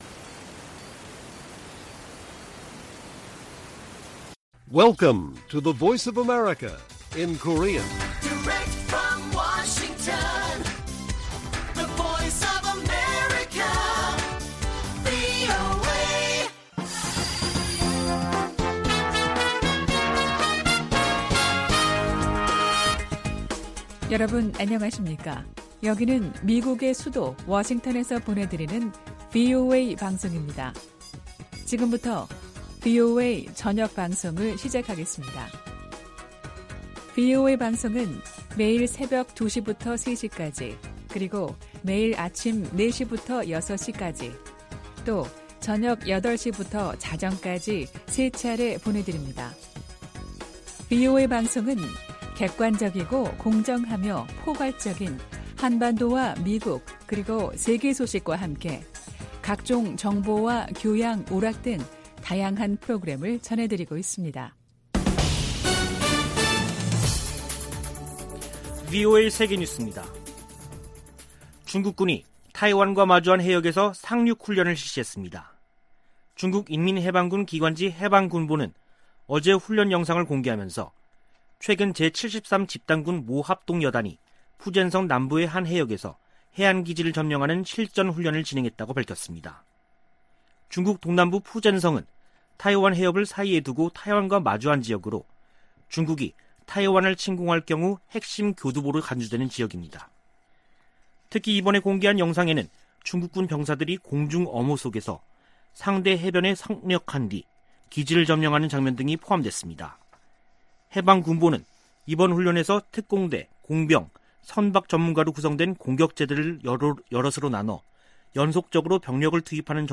VOA 한국어 간판 뉴스 프로그램 '뉴스 투데이', 2021년 10월 12일 1부 방송입니다. 김정은 북한 국무위원장은 미국과 한국이 주적이 아니라면서도 핵무력 증강 지속 의지를 확인했습니다. 미국 정부의 대북정책을 지지하는 미국인이 작년보다 감소한 조사 결과가 나왔습니다. 옥스포드 사전에 '오빠' 등 한국어 단어 26개가 추가됐습니다.